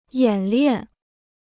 演练 (yǎn liàn): to do a drill; to practice.